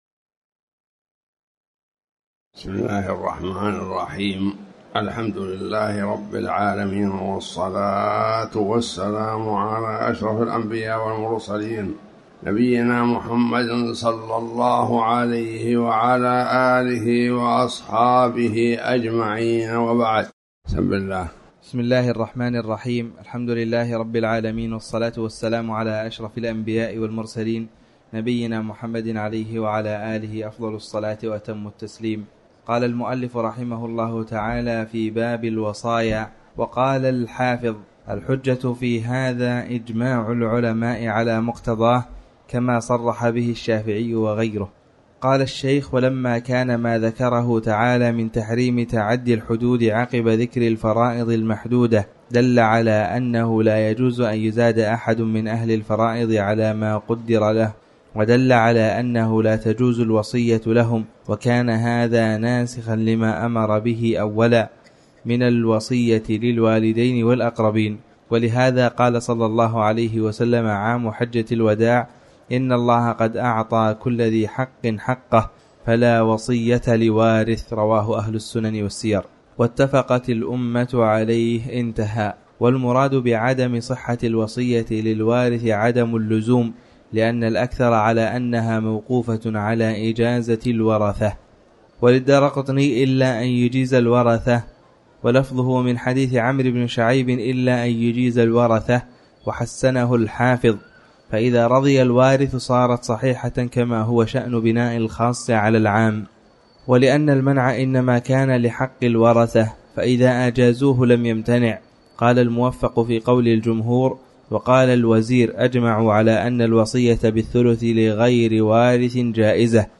تاريخ النشر ٢٣ رجب ١٤٤٠ هـ المكان: المسجد الحرام الشيخ